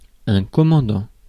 Ääntäminen
Ääntäminen France: IPA: [kɔ.mɑ̃.dɑ̃] Haettu sana löytyi näillä lähdekielillä: ranska Käännös Substantiivit 1. командир {m} 2. комендант 3. майор Suku: m .